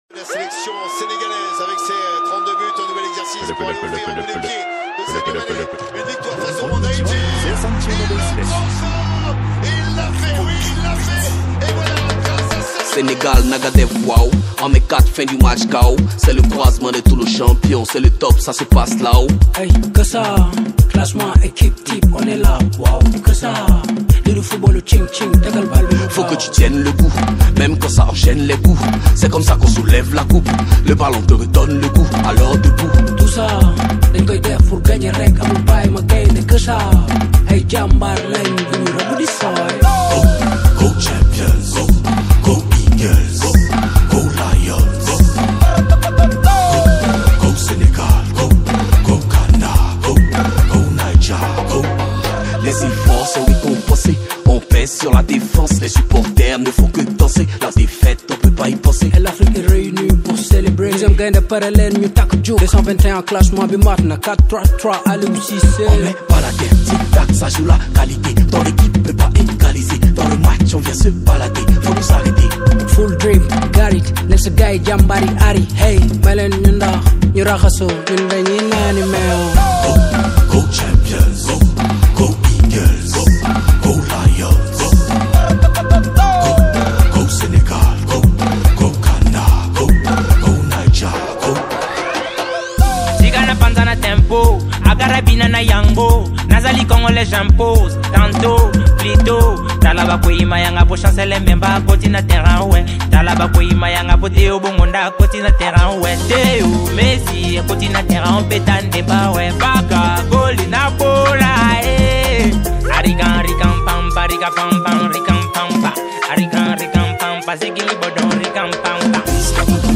| World